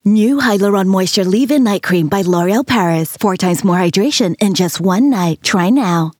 Female
Showreel
LOREAL Low Tone